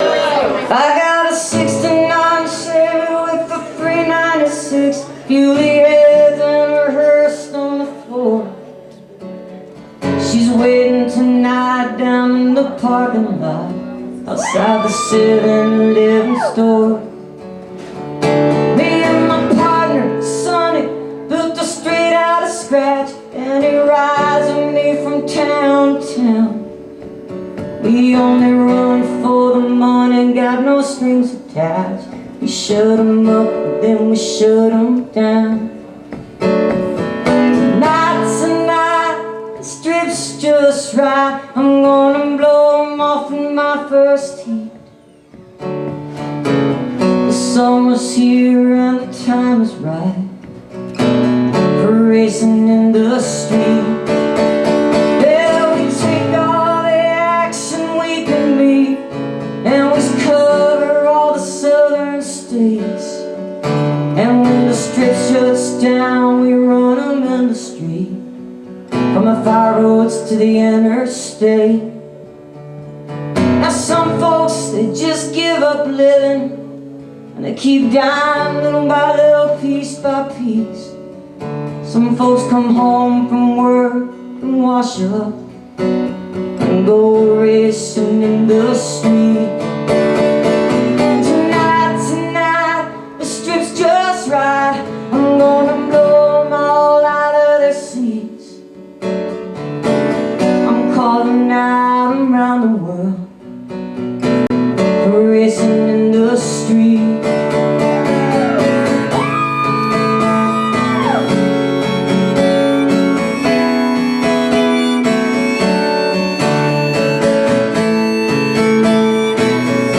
audio capture from youtube